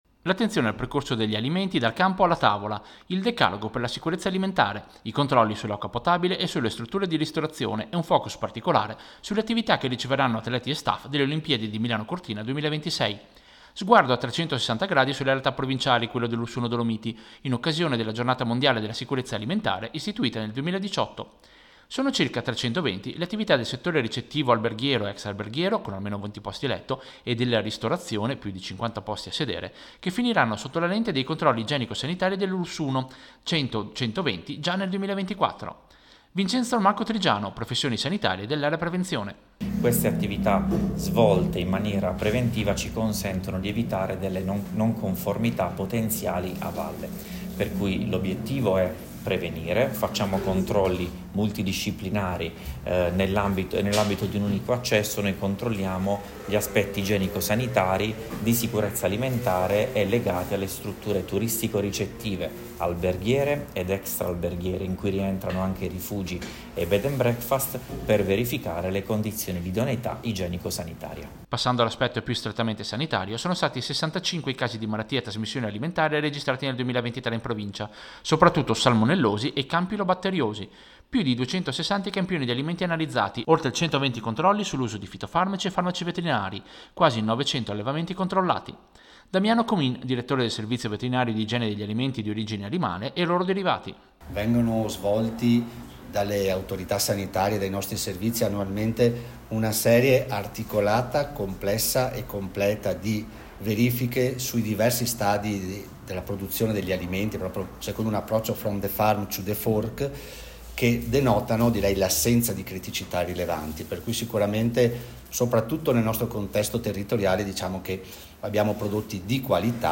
Radio-Piu-Servizio-Giornata-mondiale-sicurezza-alimentare.mp3